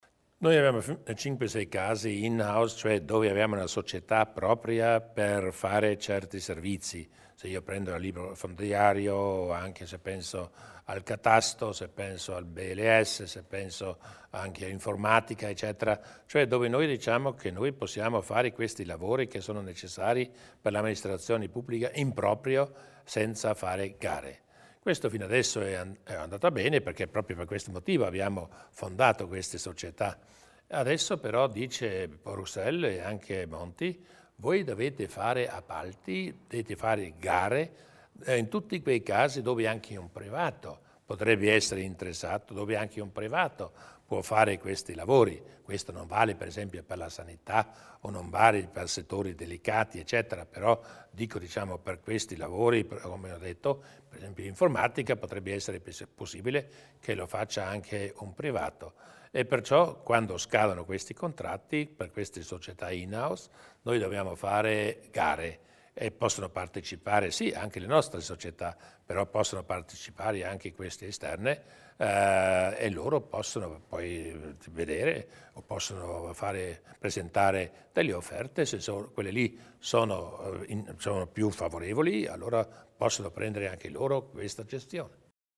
Il Presidente Durnwalder illustra i possibili cambiamenti della società "in house"